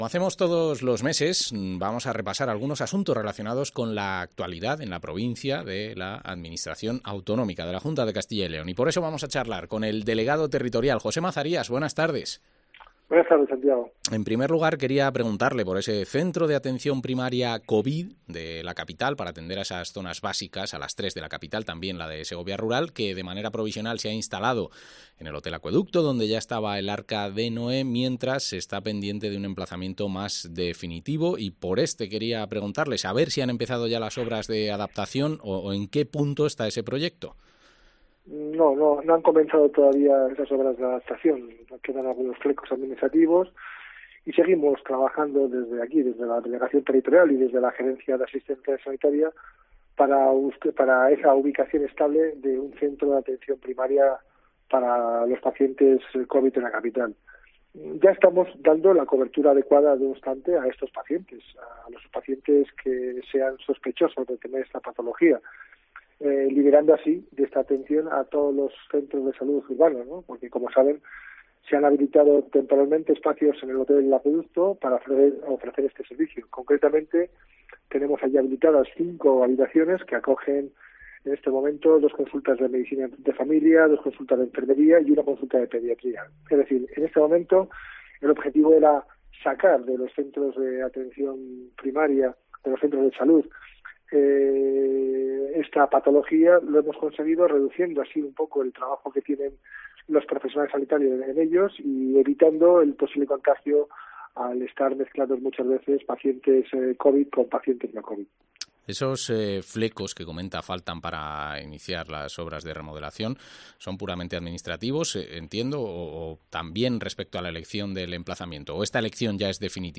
Entrevista al delegado territorial de la Junta, José Mazarías